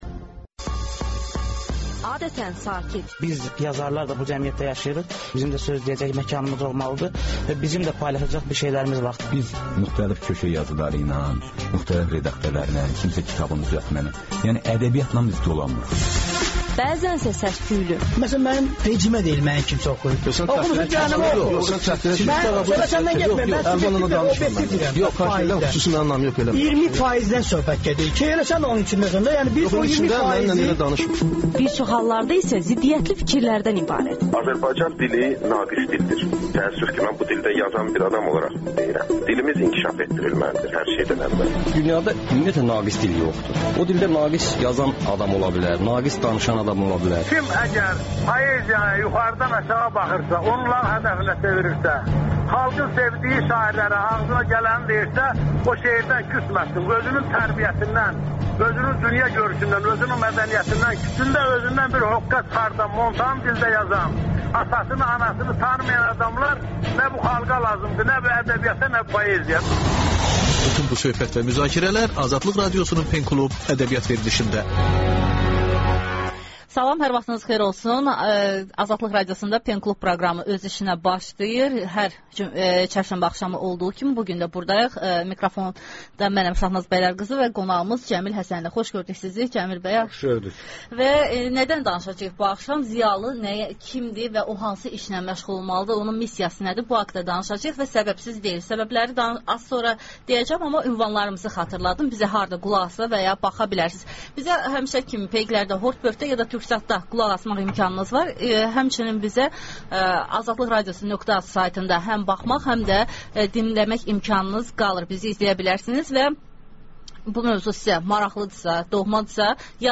Professor Cəmil Həsənli ilə yazıçı Afaq Məsudun radiodebatı